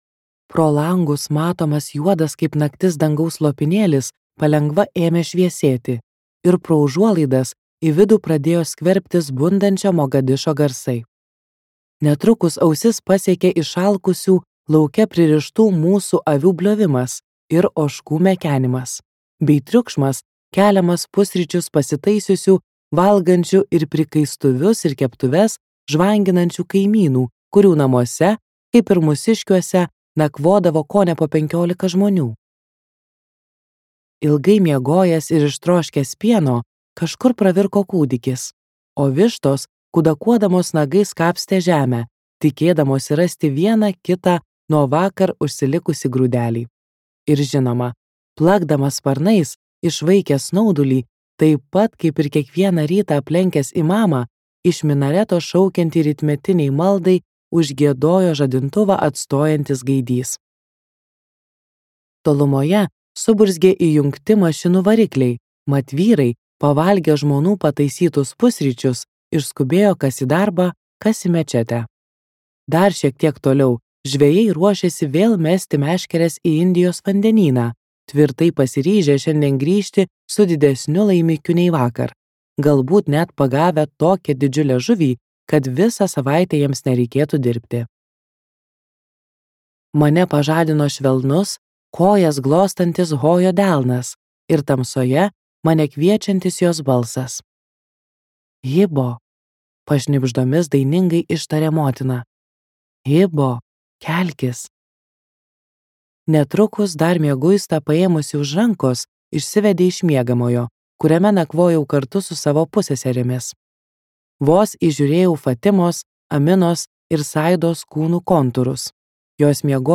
Tikra istorija | Audioknygos | baltos lankos